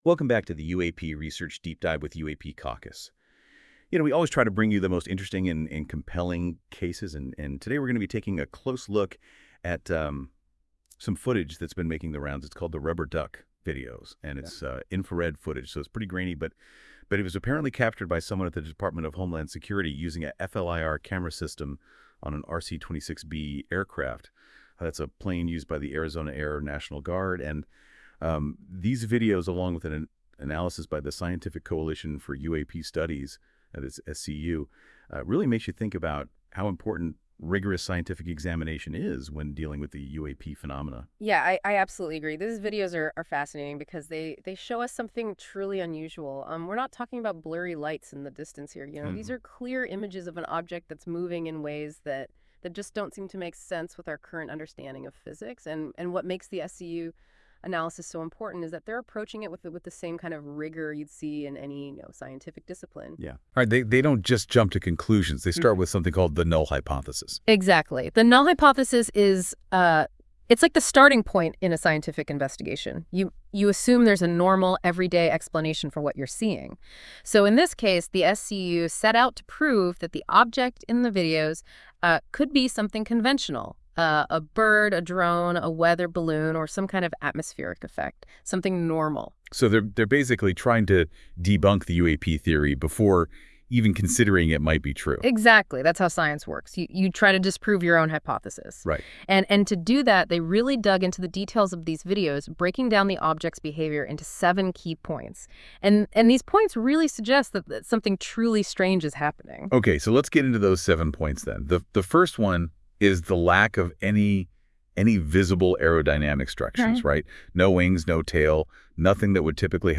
This AI-generated audio may not fully capture the research's complexity.
Audio Summary